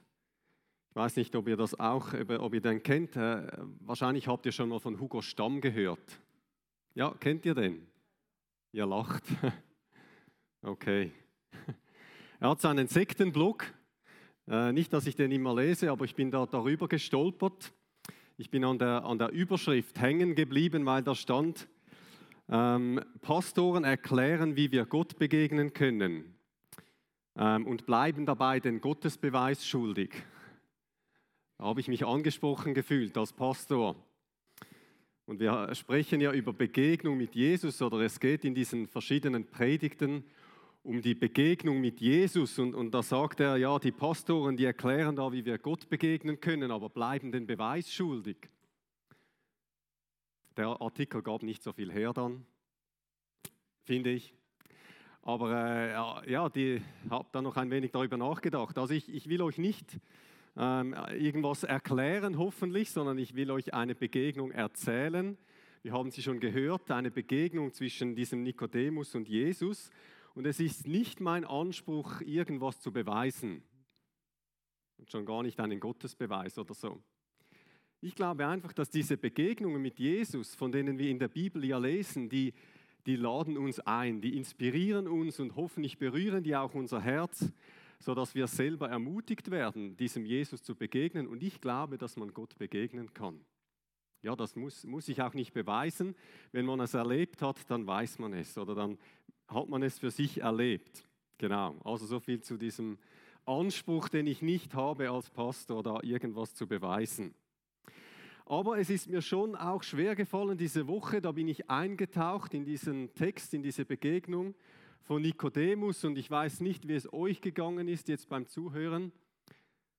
Predigt-16.07.mp3